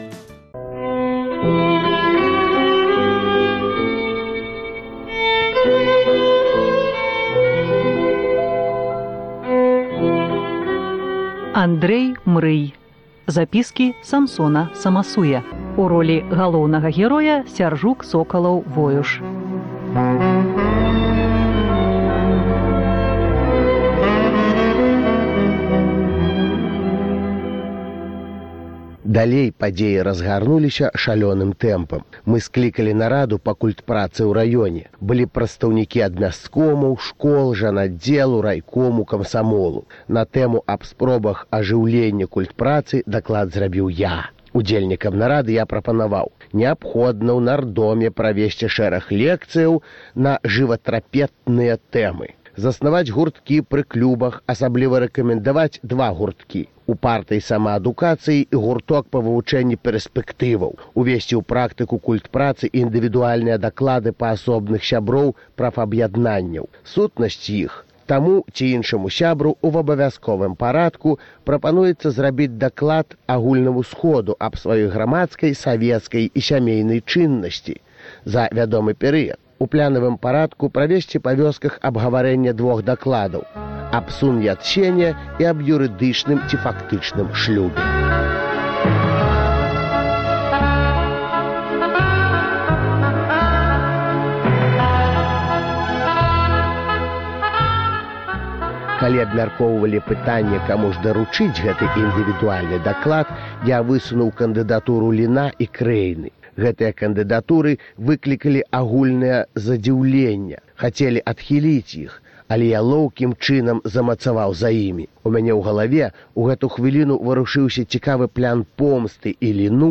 Камэнтуе паэт, вязень ГУЛАГу Сяргей Грахоўскі.